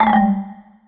《キャンセル・閉じる》フリー効果音
キャンセルボタンや閉じるボタンを押した時に鳴りそうな効果音。ちょっと不機嫌だったり不満な感じもする。
cancel-close.mp3